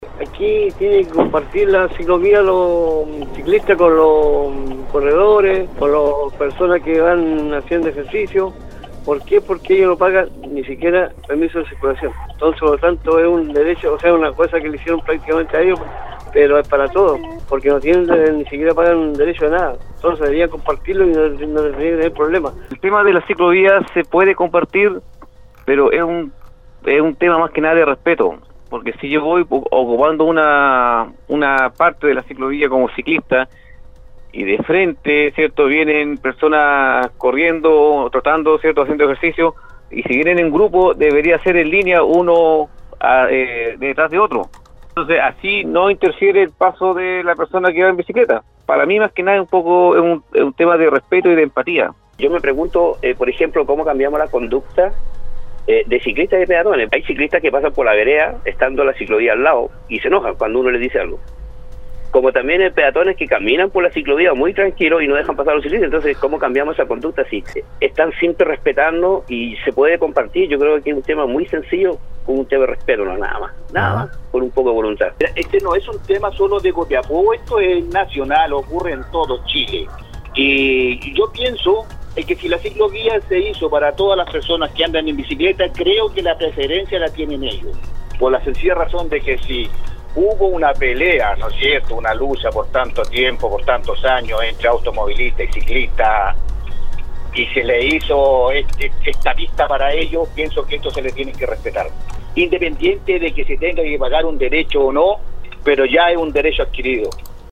Durante la mañana de este miércoles, en el programa Al Día de Nostálgica, se desarrolló un nuevo foro, esta vez centrado en la relación que existe en las ciclo vías que se encuentran distribuidas por las comunas de la región de Atacama, entre los ciclistas y las personas que practican actividad física a pie, como trote, correr o simplemente caminar.
Al ser consultados los auditores sobre cómo percibían esta convivencia en las vías exclusivas, fueron diversas las reacciones, donde las opiniones indicaron que en general no se percibe una interacción negativa, pero si se presentan algunas incomodidades sobre todo cuando se utilizan las vías de manera invasiva o de forma descuidada ante el flujo de otras personas: